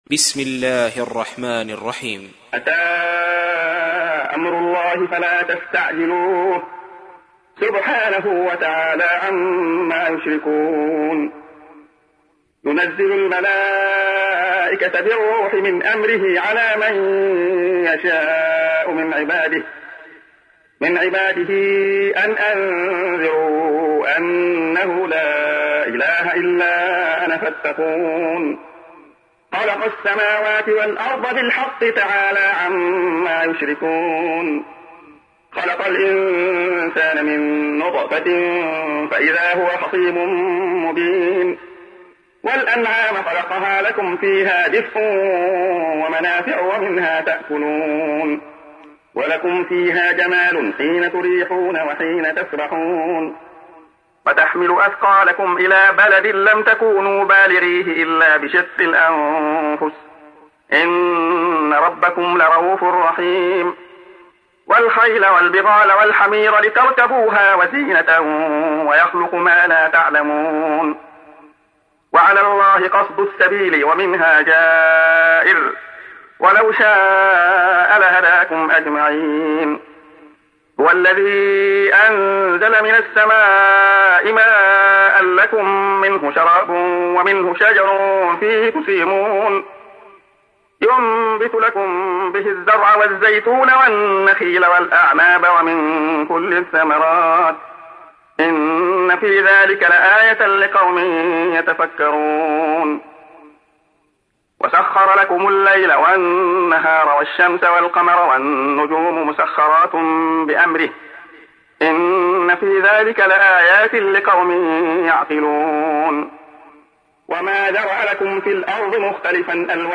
تحميل : 16. سورة النحل / القارئ عبد الله خياط / القرآن الكريم / موقع يا حسين